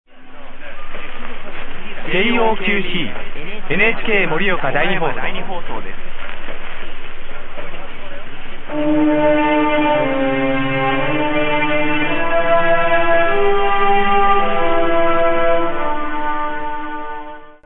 JOQC NHK2 Morioka, on 1386 kHz, signing off, just to show how well some Japanese stations can be heard.
1386_JOQC_NHK2_Morioka_sign_off.mp3